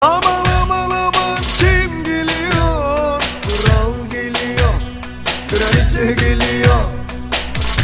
kesanli-yasam-kral-geliyor-roman-havasi-mp3cut.mp3